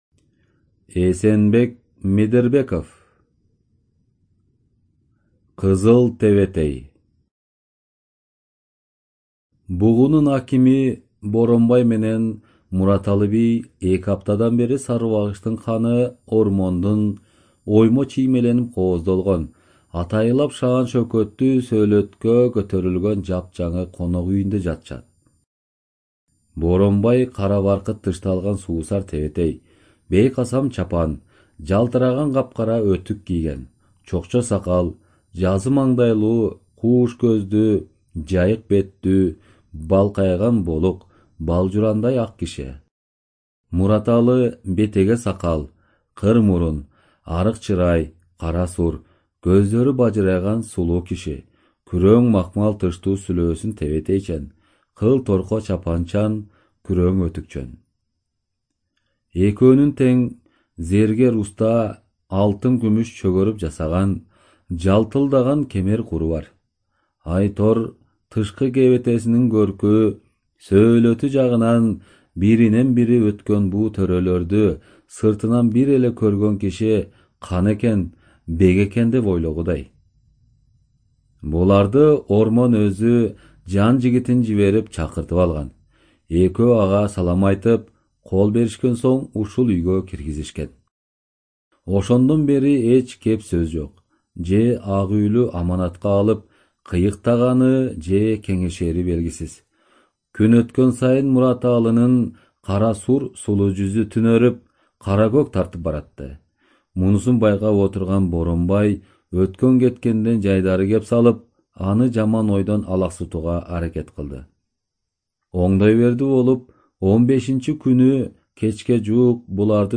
Студия звукозаписиКыргызская Республиканская специализированная библиотека для слепых и глухих